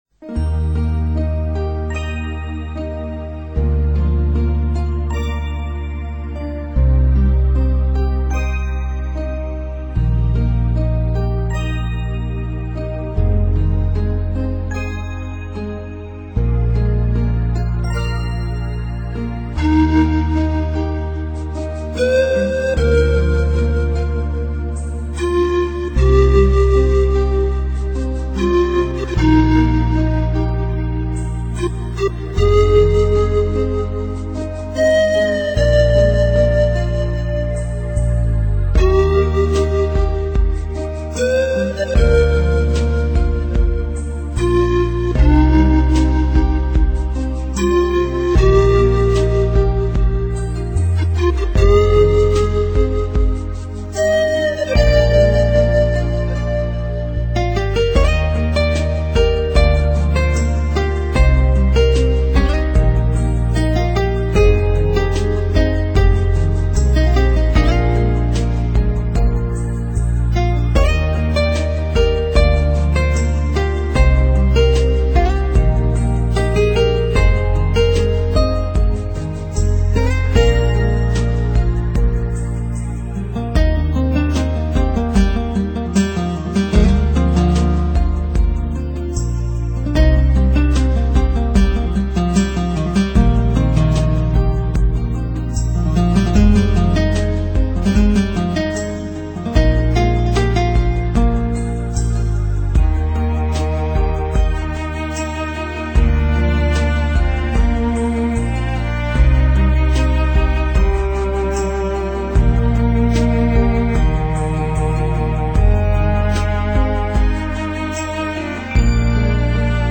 渐渐地，您的心境却如湖水，晶莹见底 14首空灵仙乐，居然点滴描绘出多层次变化的湖畔景致！
音场与录音质感 音质感与聆赏惊艳度，自然是更上一层楼！